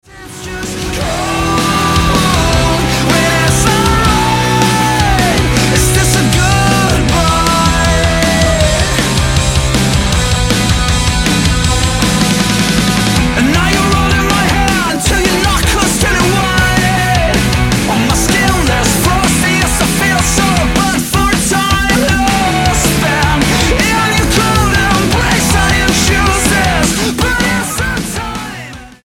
post-hardcore
Hard Music